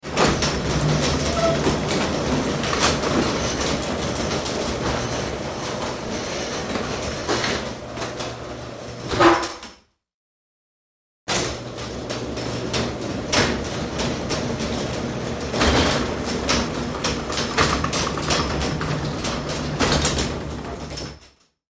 Garage Door 2; Electric Garage Door Opener Opening And Closing Garage Door. Medium Perspective.